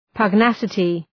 Προφορά
{pəg’næsətı}